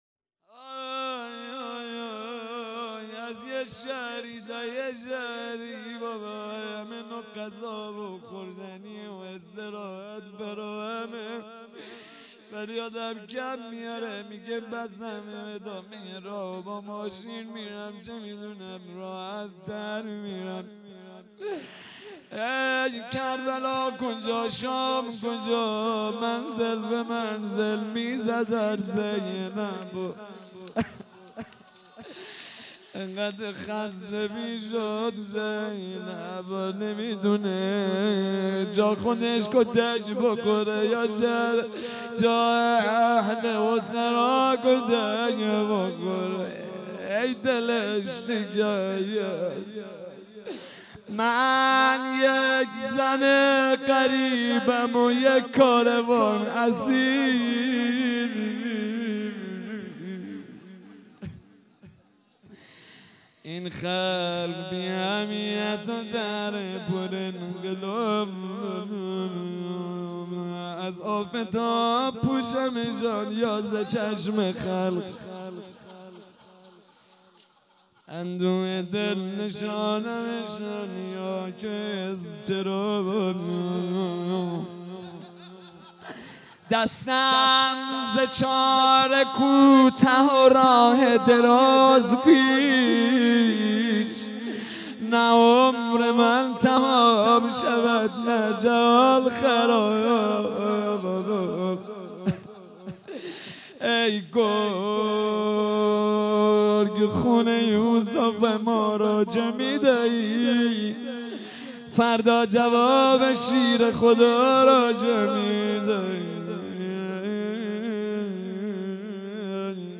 جلسه هفتگی هیئت یازهرا(س)